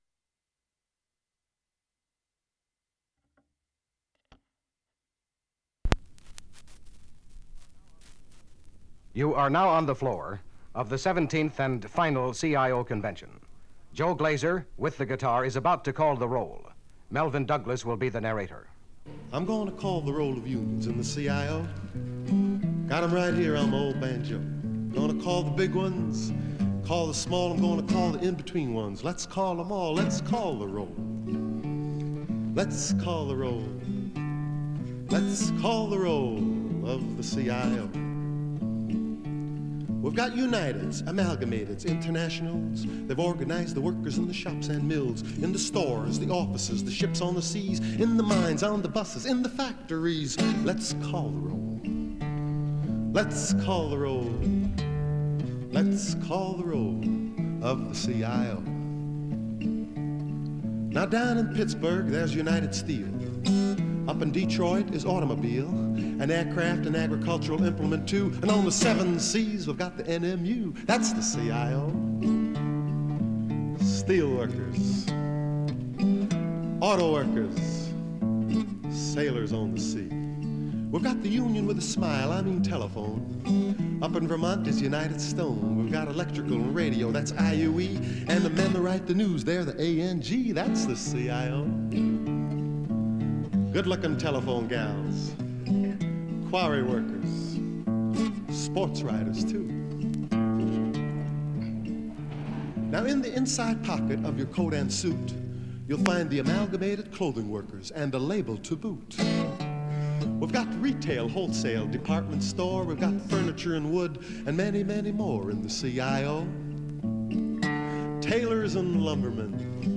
Guitar Accompinant